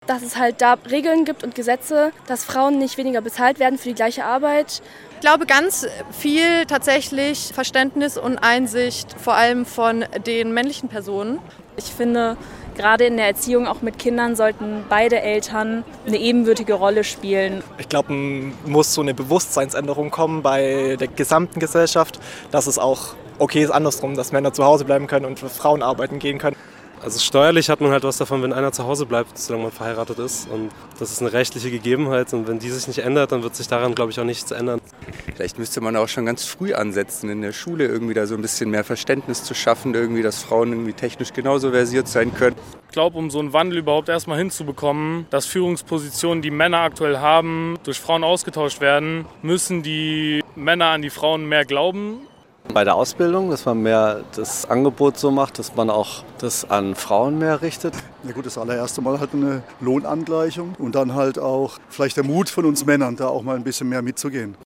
Radio- und Video-Umfrage in der Freiburger Innenstadt im Rahmen des BOGY-Praktikums im SWR Studio Freiburg.